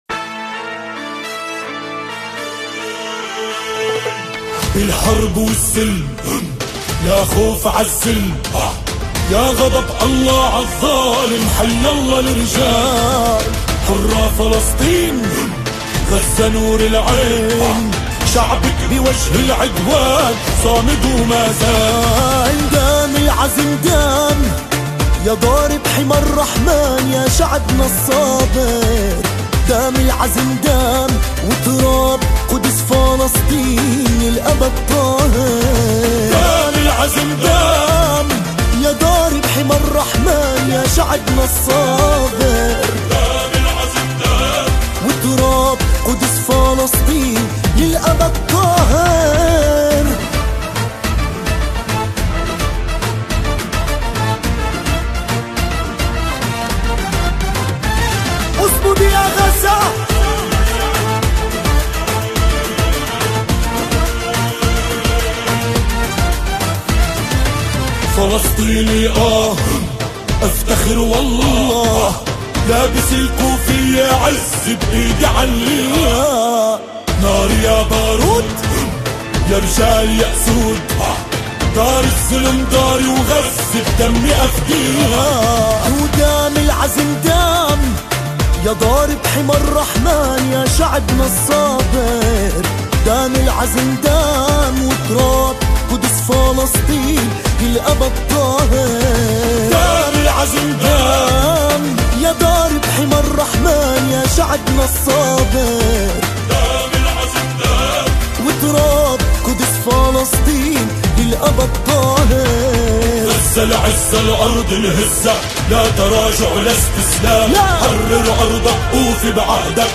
سرودهای فلسطین